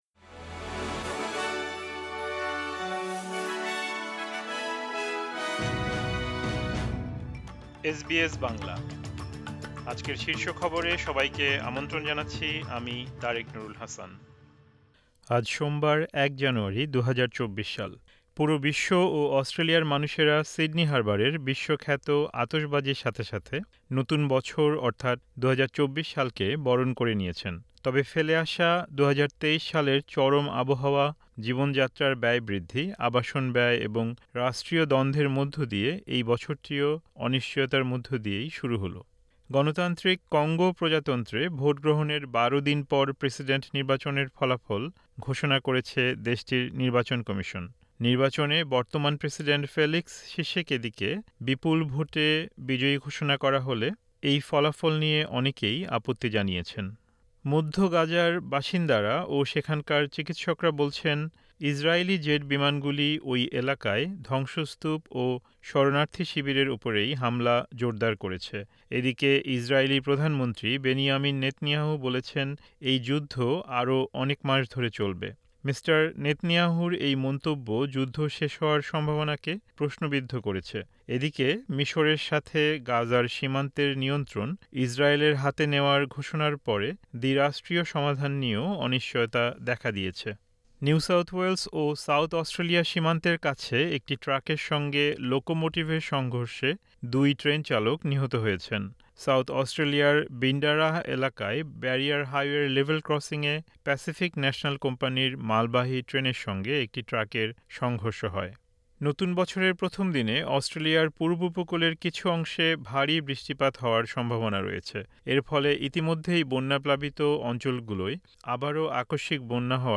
এসবিএস বাংলা শীর্ষ খবর: ১ জানুয়ারি, ২০২৪